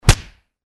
Звук удара по лицу как в болливудском кино